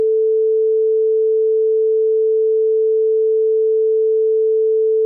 Um das zu verstehen, muss man wissen, dass der reinste mögliche Ton aus einer gleich schwingenden Welle besteht, der so genannten „Sinus-Welle“:
Sinus Welle